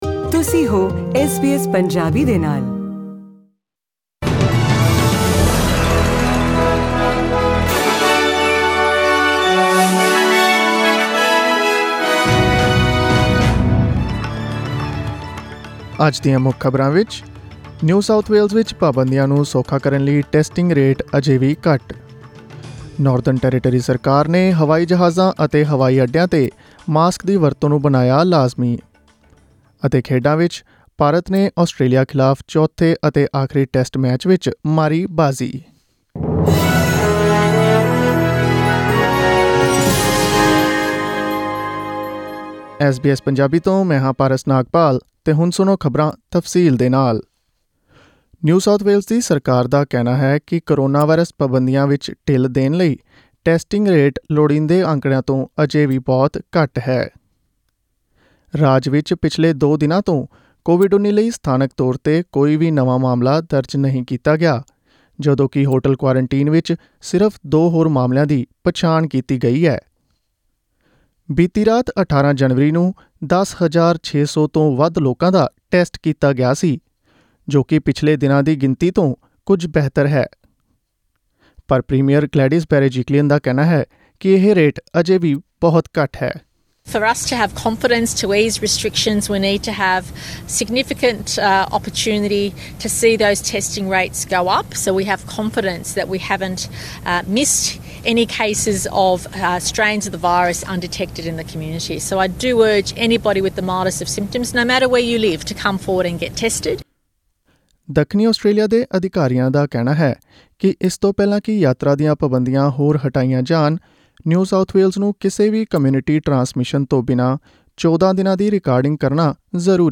Listen to the bulletin in Punjabi by clicking on the audio icon in the picture above.